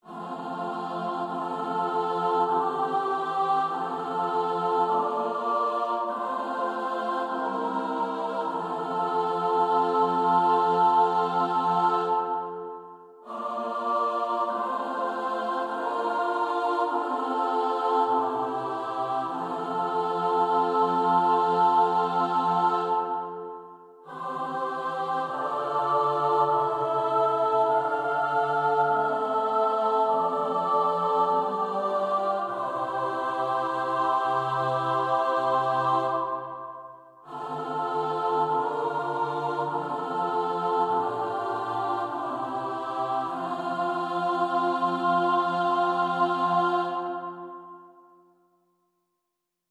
4-Part Chorales that aren’t Bach
Comments: The last two phrases contain several tonicizations including a tonicized half cadence in the third phrase.